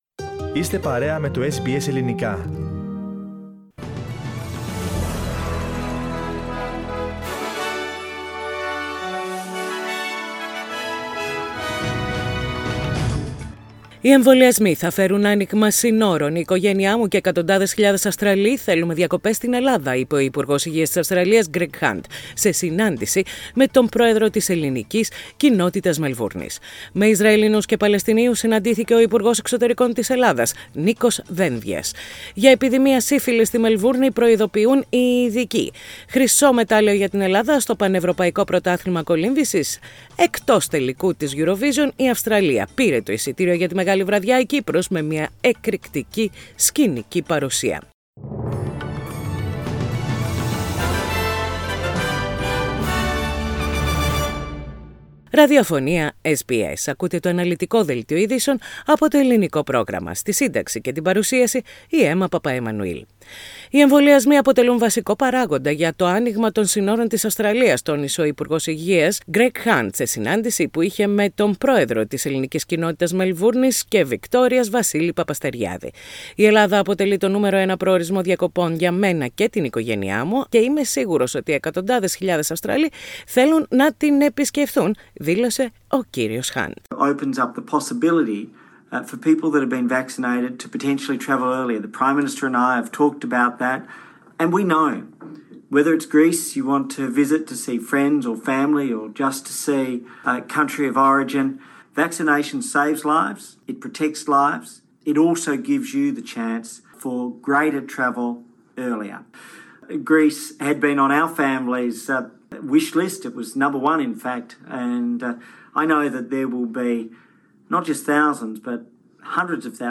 Ειδήσεις στα Ελληνικά - Τετάρτη 19.5.21
Οι κυριότερες ειδήσεις της ημέρας από το Ελληνικό πρόγραμμα της ραδιοφωνίας SBS.